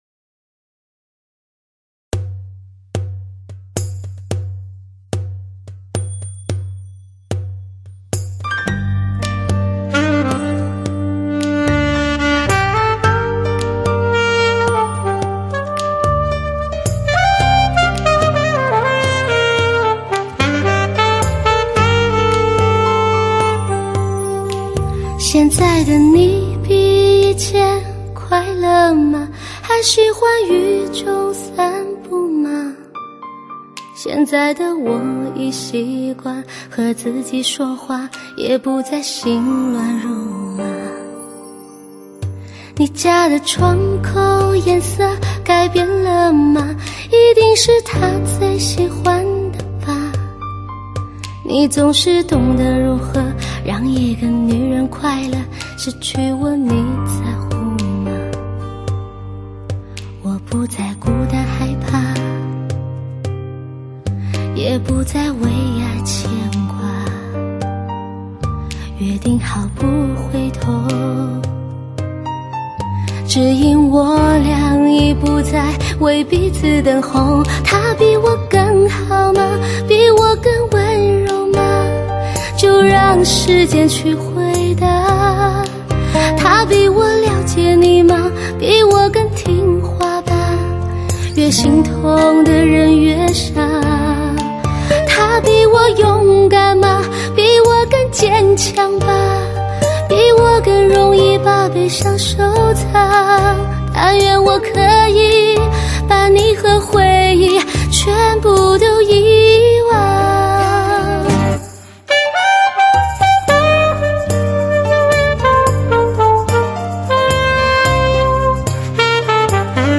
这是一种什么声音啊，时而像露珠的呢喃，时而像岩浆的涌动，
时而让人幻入远古的星空倾听天女的咒语，